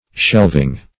Shelving \Shelv"ing\, a.